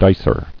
[dic·er]